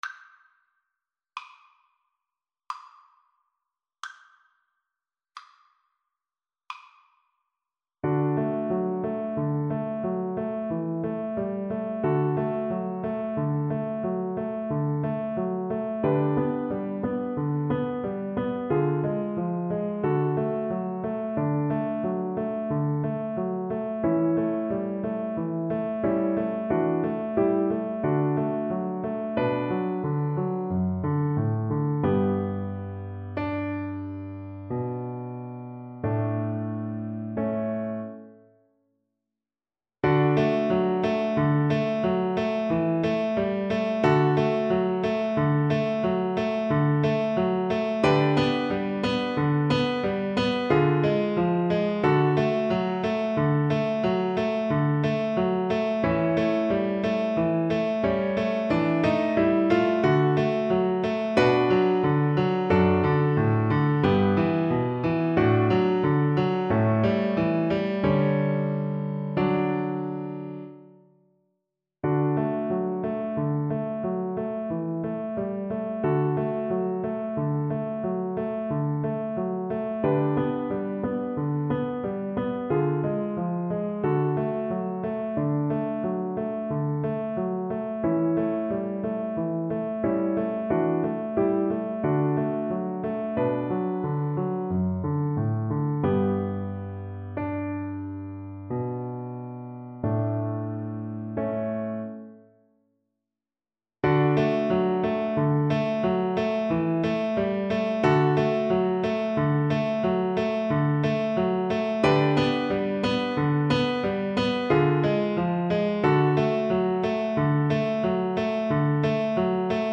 Free Sheet music for Cello
Cello
D major (Sounding Pitch) (View more D major Music for Cello )
Andante =60
3/4 (View more 3/4 Music)
Classical (View more Classical Cello Music)